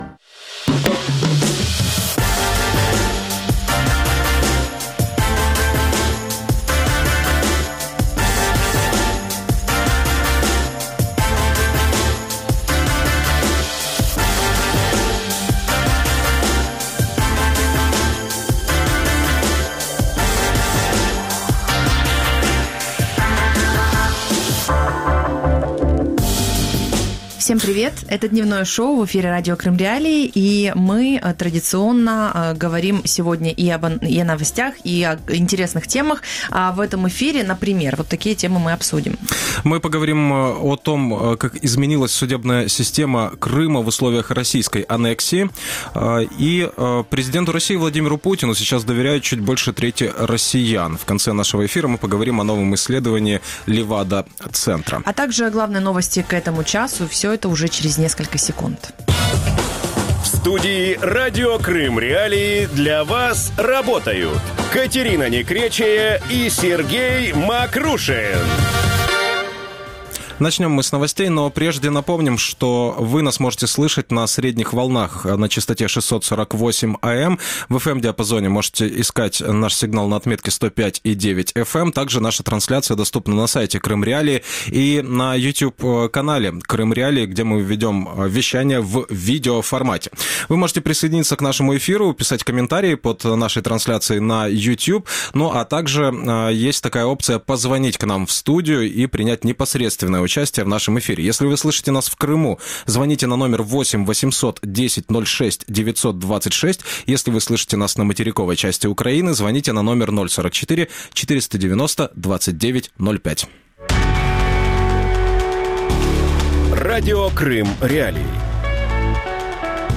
Кто судит крымчан? | Дневное ток-шоу